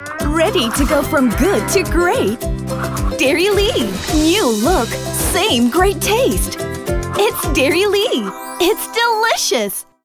Female
Showreel
DairyLea ENG - Cheerful/Happy Tone